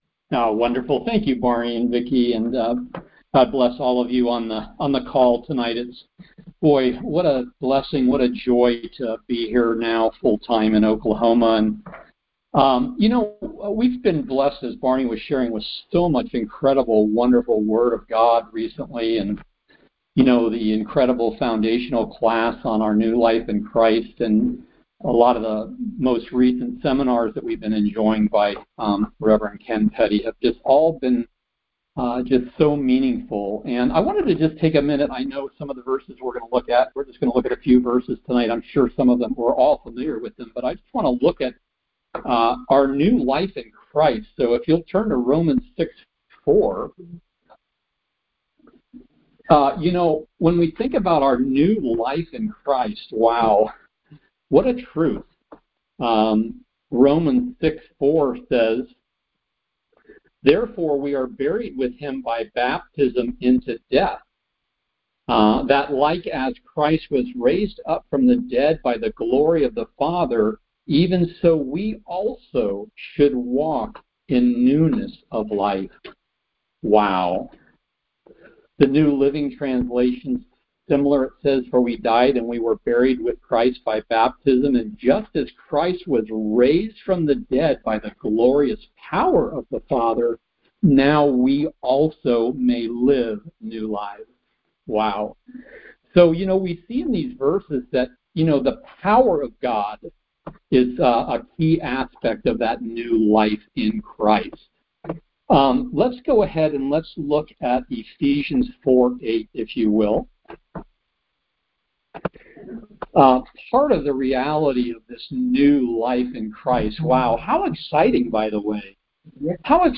The New Man/Mindset of Victory Details Series: Conference Call Fellowship Date: Thursday, 10 April 2025 Hits: 410 Scripture: Romans 5:1 Play the sermon Download Audio ( 9.38 MB )